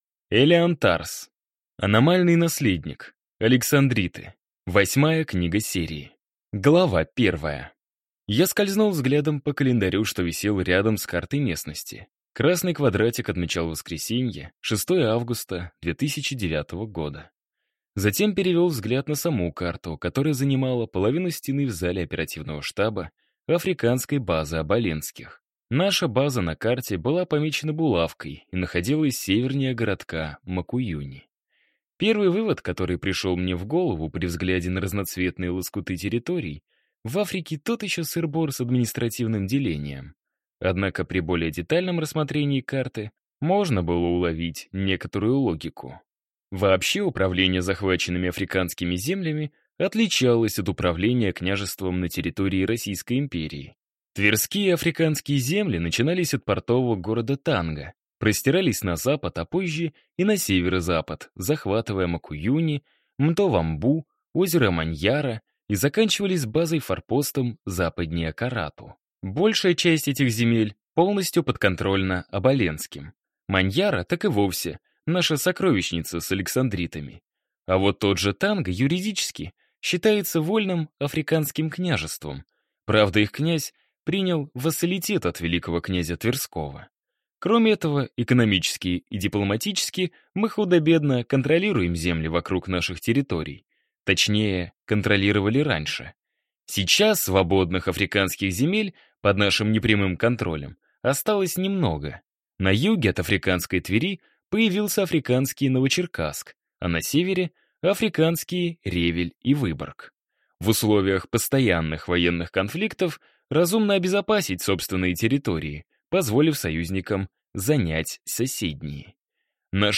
Аудиокнига Аномальный Наследник. Александриты | Библиотека аудиокниг